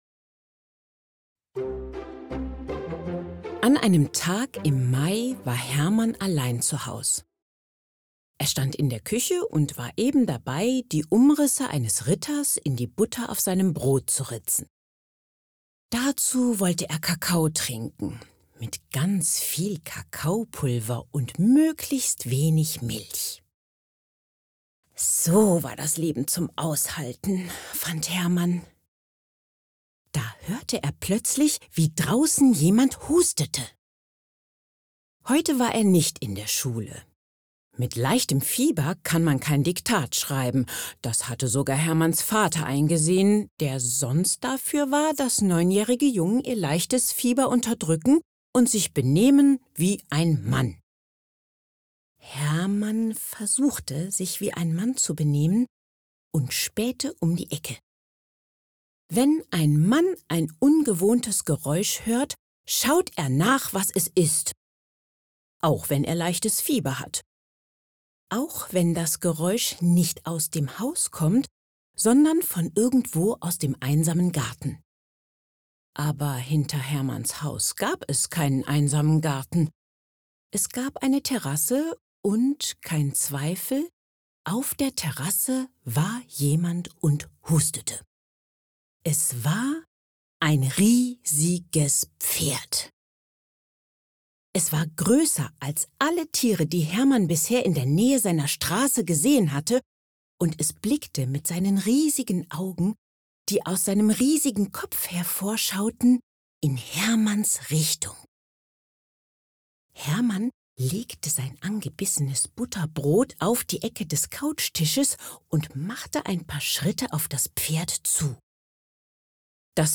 Vorlesegeschichte (Demo)
warm, markant, tief, beruhigend, erzählerisch, vernünftig, psychologisch, seriös
Montagmorgen-mit-Mouth-Declick.mp3